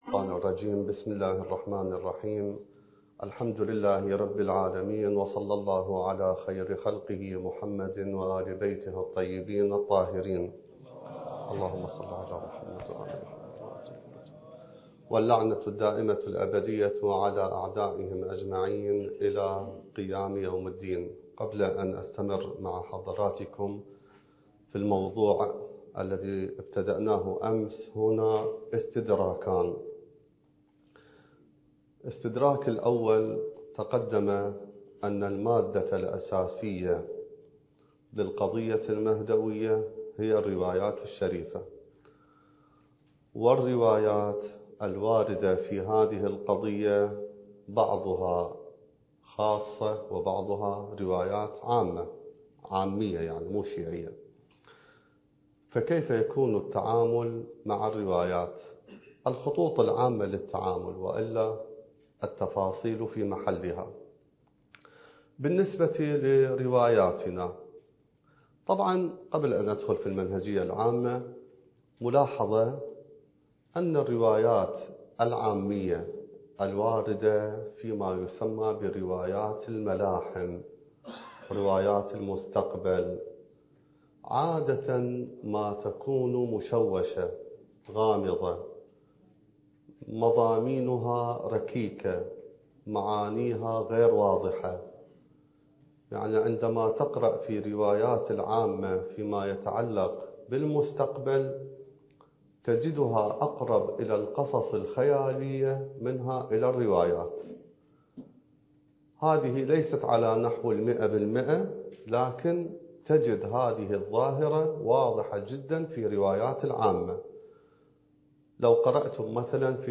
الدورة المهدوية الأولى المكثفة (المحاضرة الحادية عشر)
المكان: النجف الأشرف